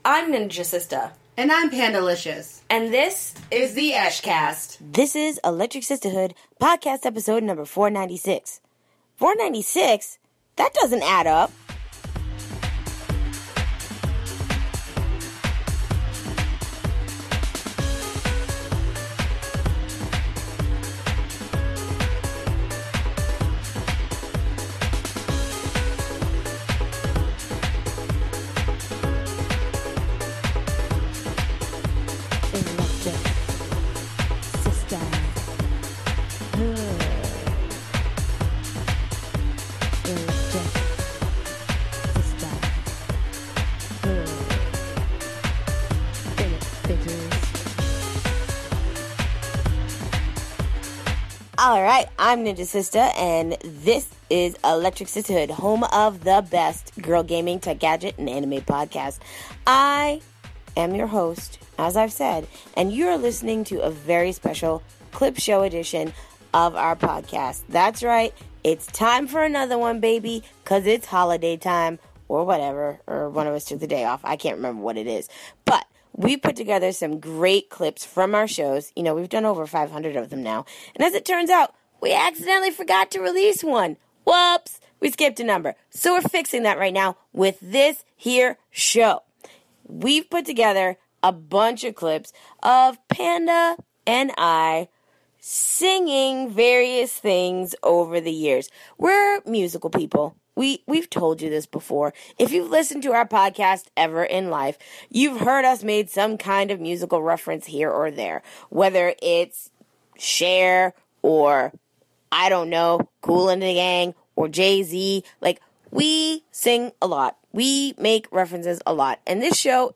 So rather than pretend the number 496 simply doesn't exist, and because this week's show is a clip show, we've decided to fix that error before we get much farther down the line.